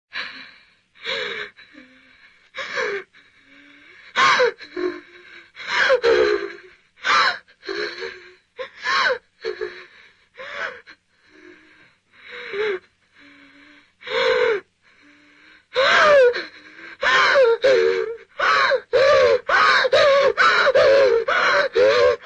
女孩颤抖哭泣音效_人物音效音效配乐_免费素材下载_提案神器
女孩颤抖哭泣音效免费音频素材下载